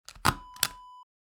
Hole punch sound effect .wav #1
Description: The sound of punching holes in paper using a hole punch
Properties: 48.000 kHz 24-bit Stereo
A beep sound is embedded in the audio preview file but it is not present in the high resolution downloadable wav file.
Keywords: punch, puncher, punching, hole, holes, perforate
hole-punch-preview-1.mp3